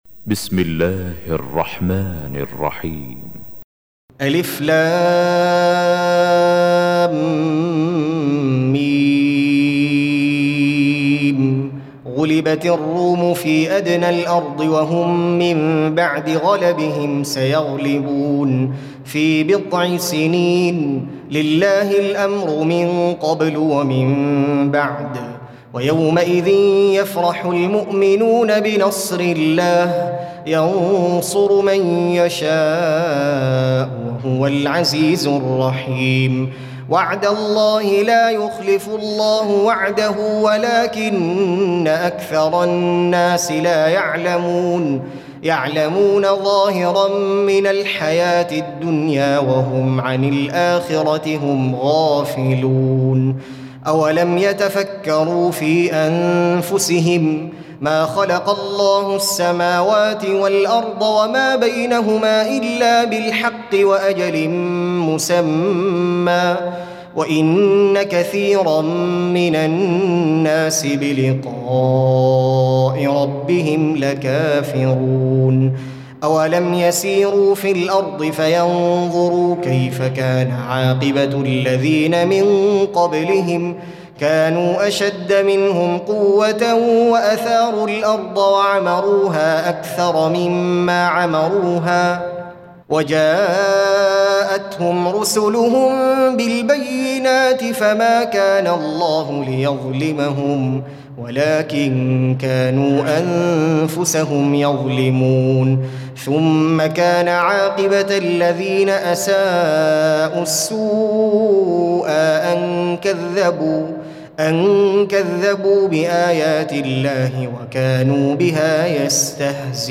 30. Surah Ar�R�m سورة الرّوم Audio Quran Tarteel Recitation
Surah Sequence تتابع السورة Download Surah حمّل السورة Reciting Murattalah Audio for 30.